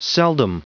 Prononciation du mot seldom en anglais (fichier audio)
Prononciation du mot : seldom